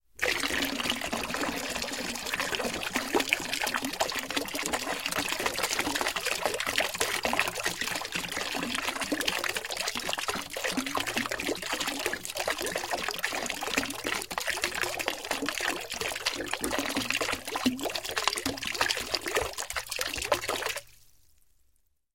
Льющаяся вода - Альтернативный вариант